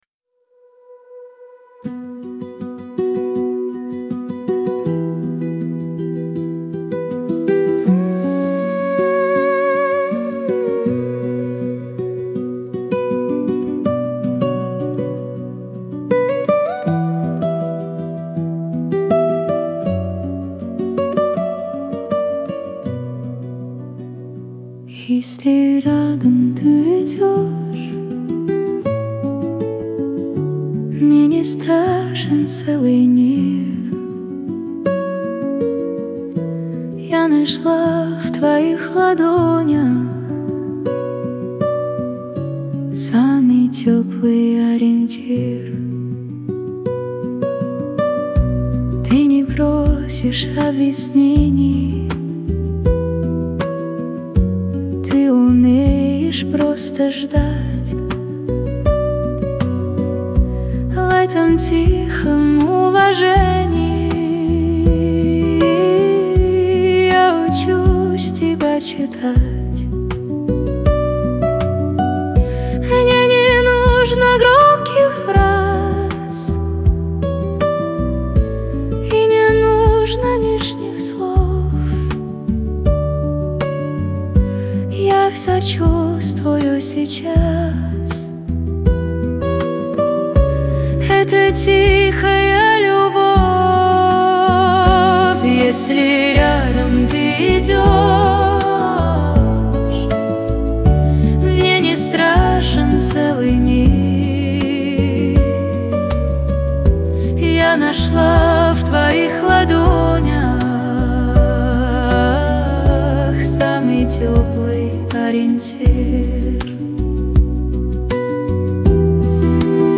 СПОКОЙНАЯ МУЗЫКА